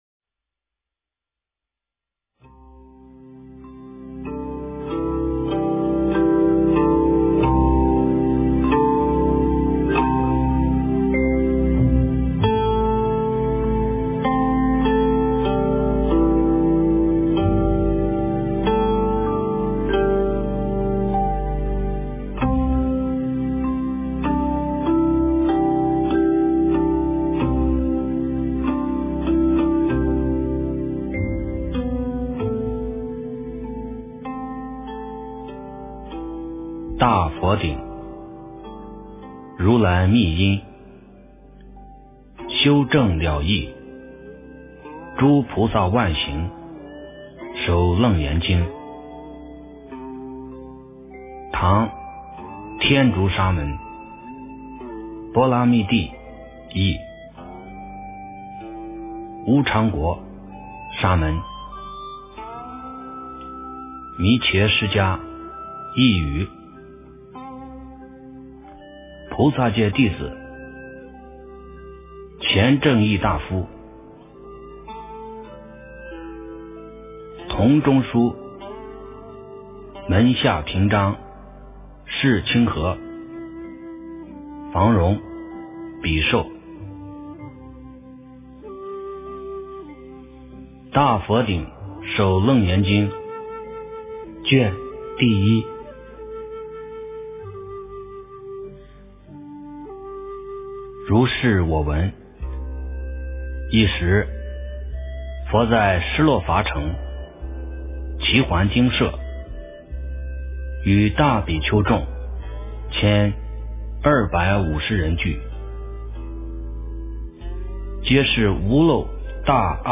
楞严经第01卷(念诵)
诵经 楞严经第01卷(念诵